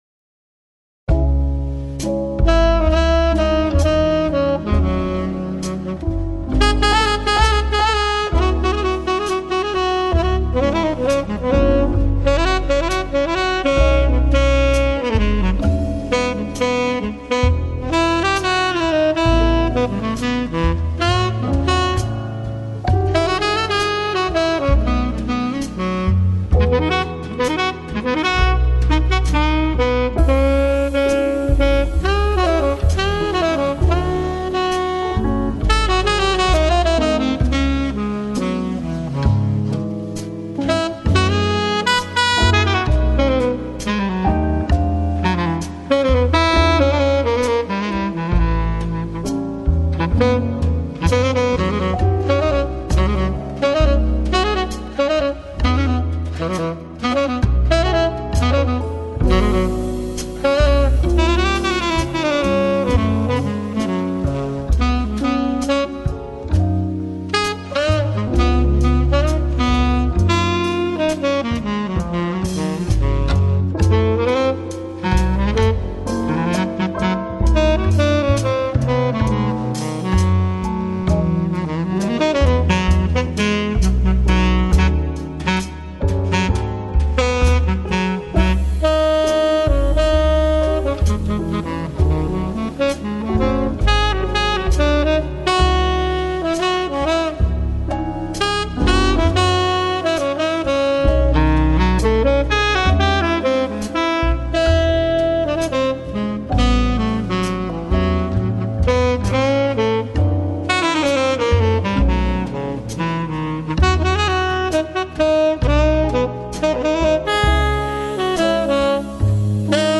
FLAC Жанр: Jazz Издание